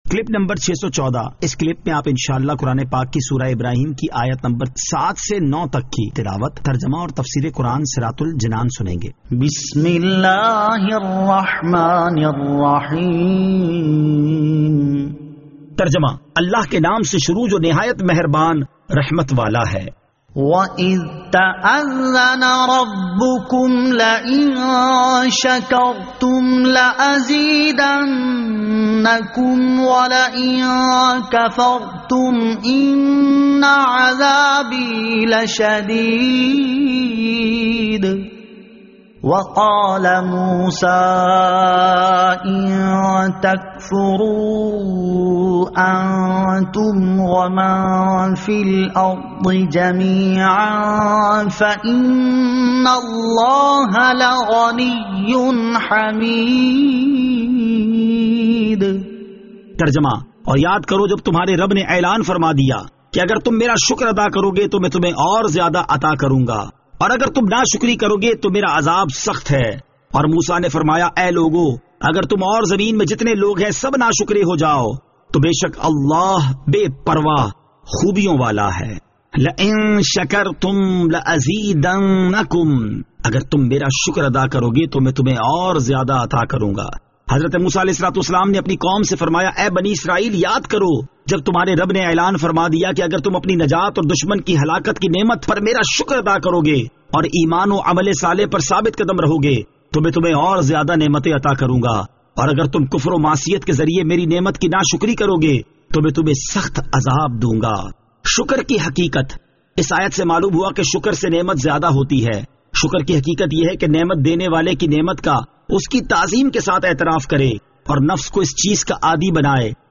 Surah Ibrahim Ayat 07 To 09 Tilawat , Tarjama , Tafseer